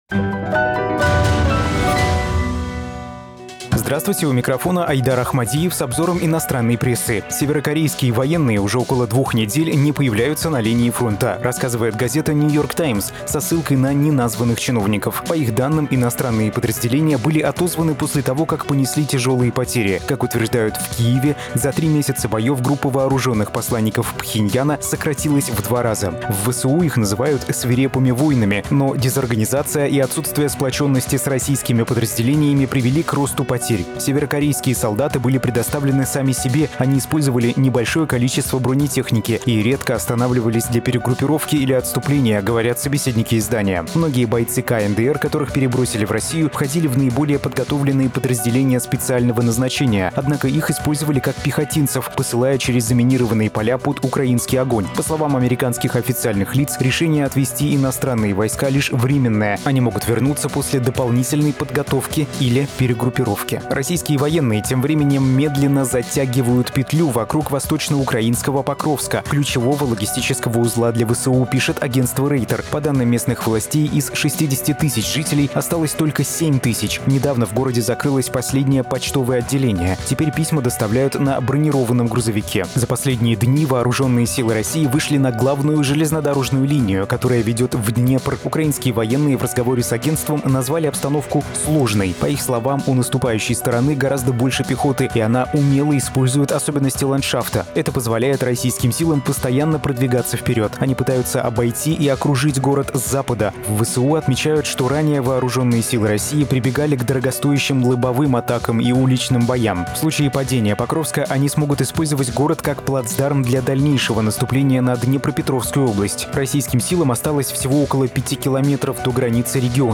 Читаем иностранную прессу